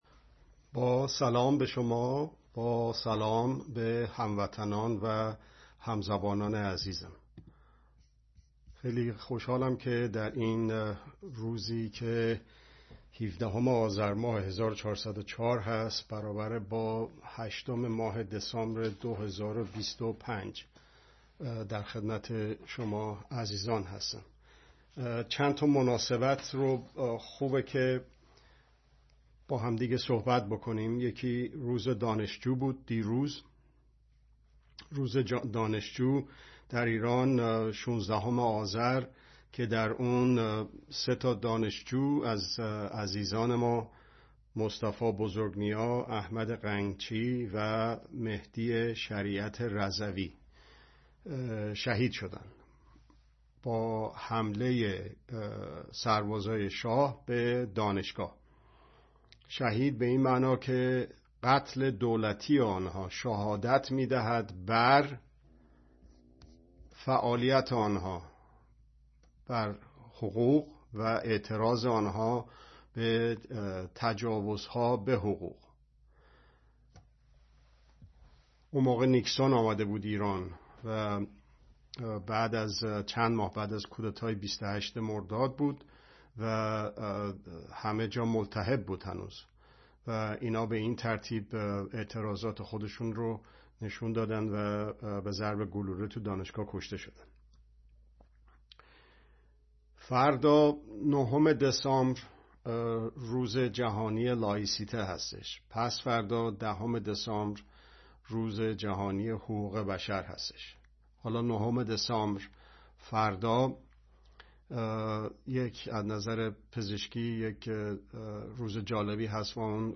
( دوشنبه‌ها ساعت۲۱ به‌وقت ایران به‌صورت زنده )